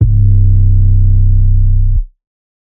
808s
Metro 808s [Not Playin].wav